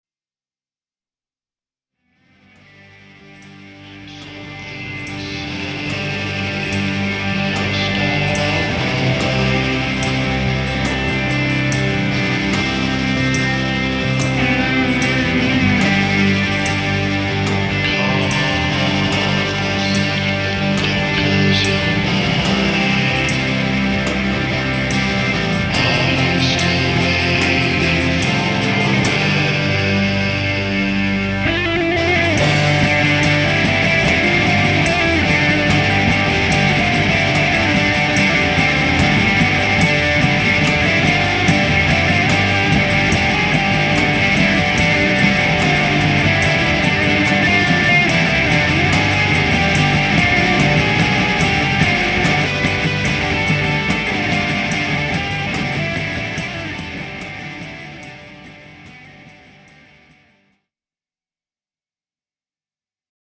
Recorded at Larry-O-Shack + The Drigh Room